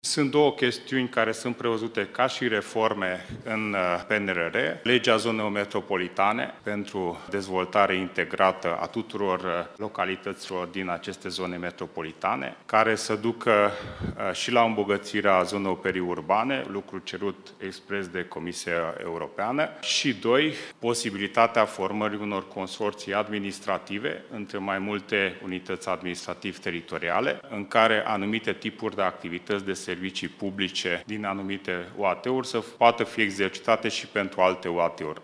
Constituirea zonelor metropolitane în România se va face cu bani europeni – a menționat ministrul Dezvoltării, Cseke Attila, prezent la ședința Asociației Muncipiilor din România.